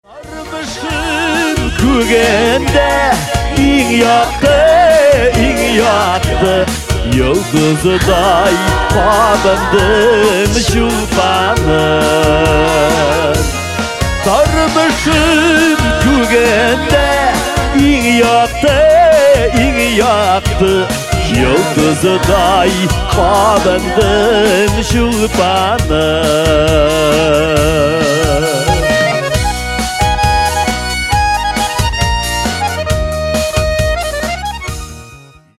Kатегория: » Татарские рингтоны